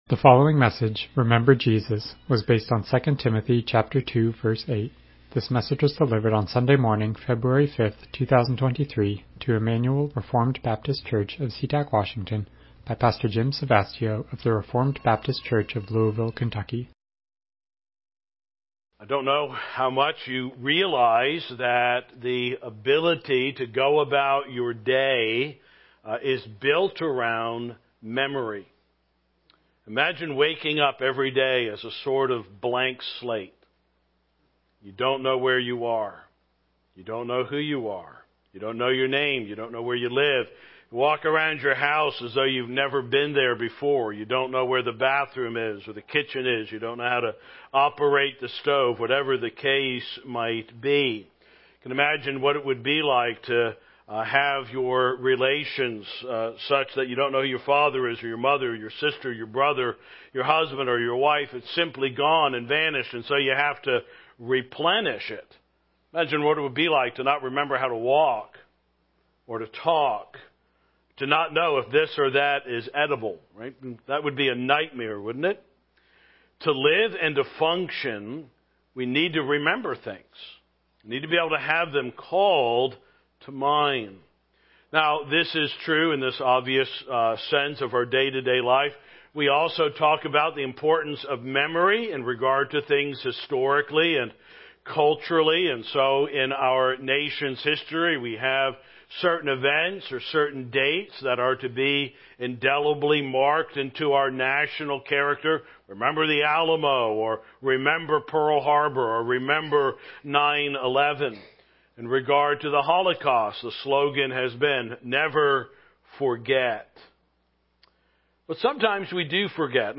Passage: 2 Timothy 2:8 Service Type: Morning Worship « What Would You Do If You Knew the World Was Ending?